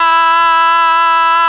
RED.OBOE  22.wav